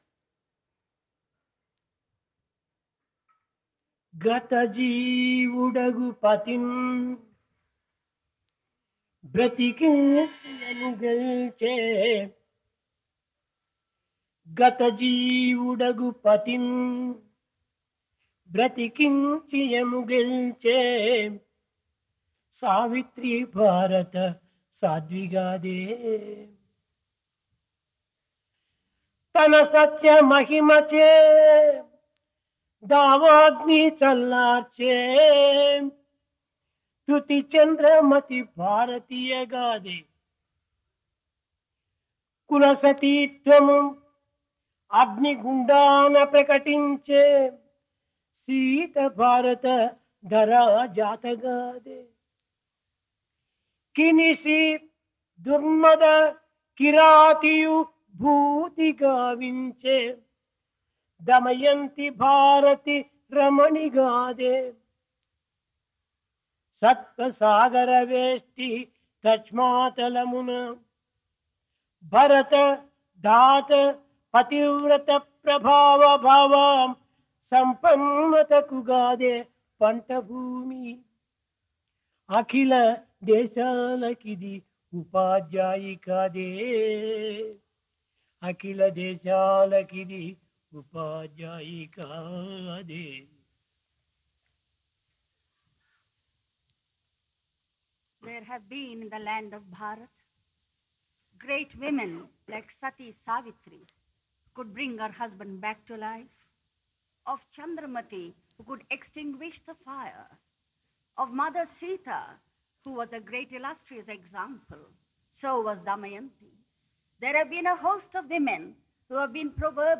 Discourse
Place Prasanthi Nilayam Occasion Ladies Day